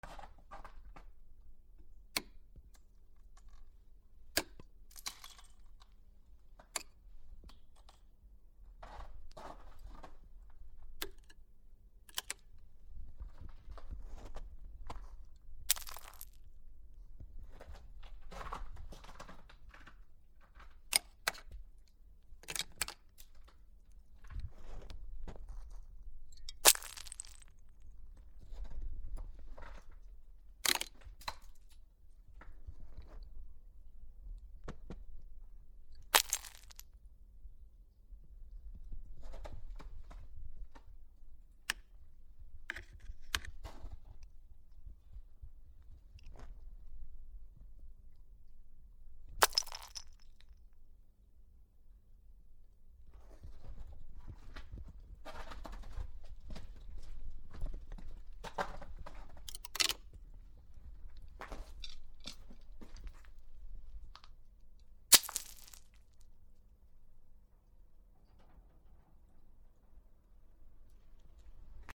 つららを折る 割る
/ M｜他分類 / L35 ｜雪・氷 /
MKH416